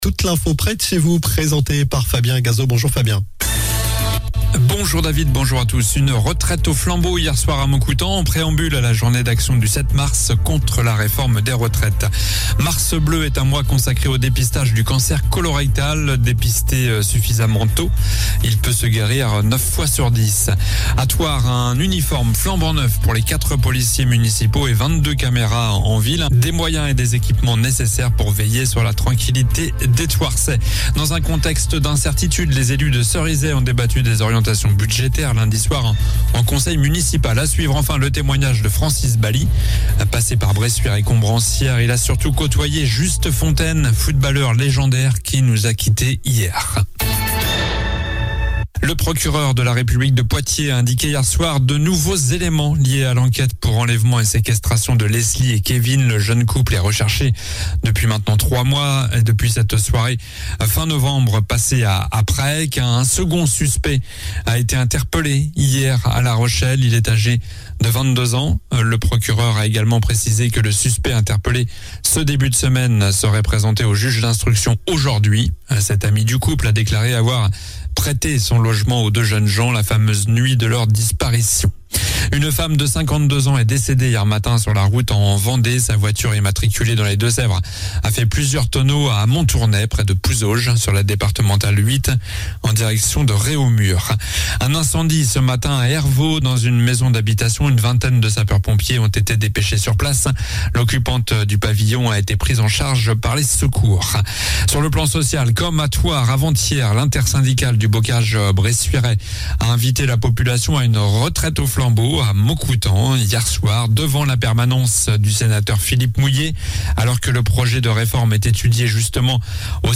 Journal du jeudi 02 mars (midi)